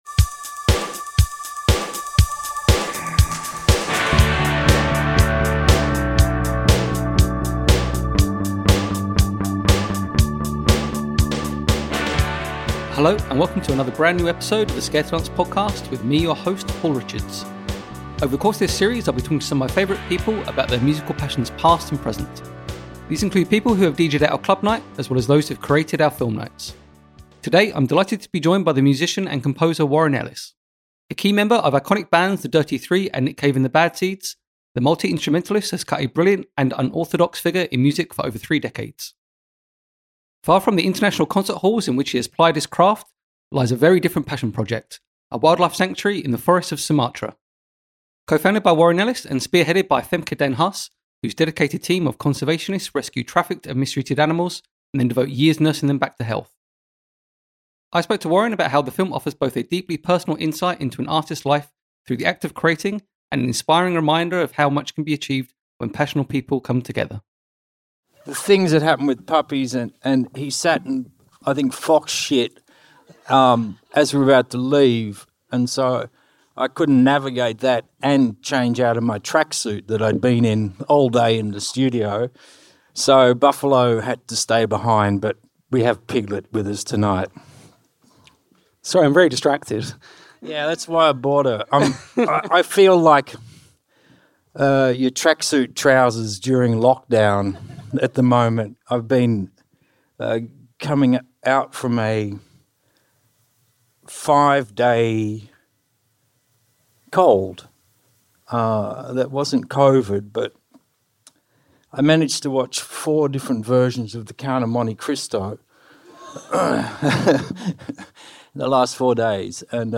They discuss Ellis Park, the documentary film about the wildlife sanctuary co-founded by Warren plus the creative journey of Nina Simone's Gum, working with Nick Cave and navigating personal breakdowns, live on stage at Picturehouse Central.